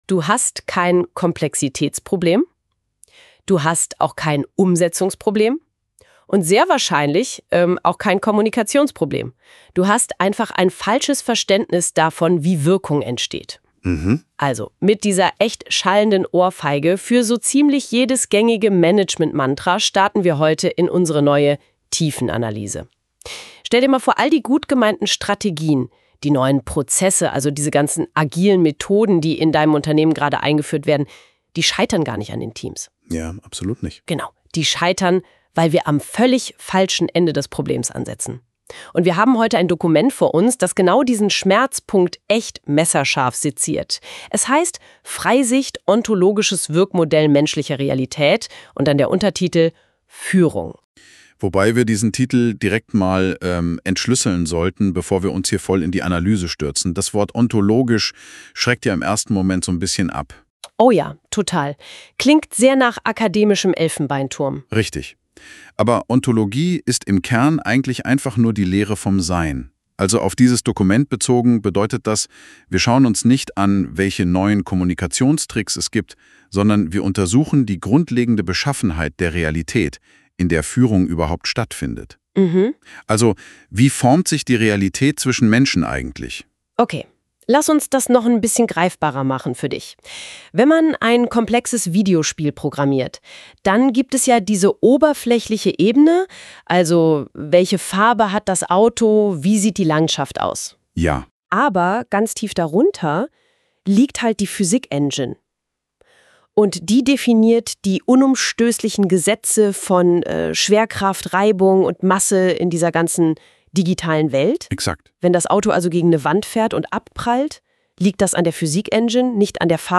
Die Stimmen, die Du hörst, sind KI-generiert.